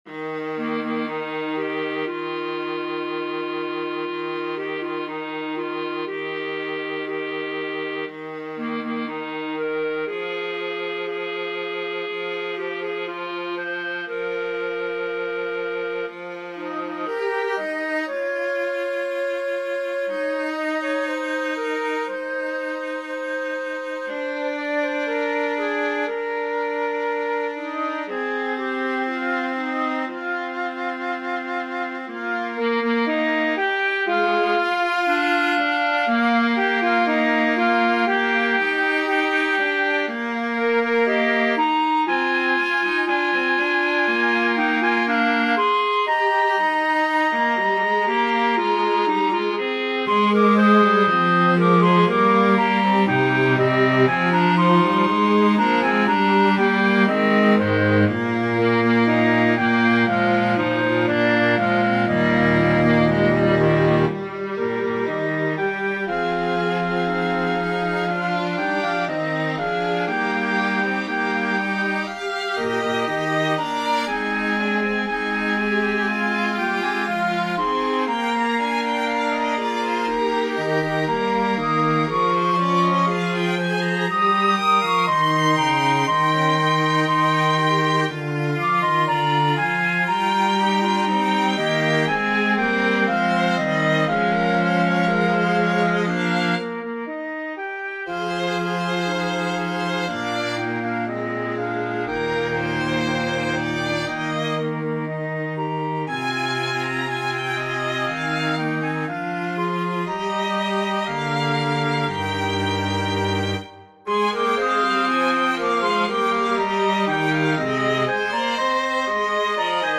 Flute,Clarinet,Violin,Viola,Cello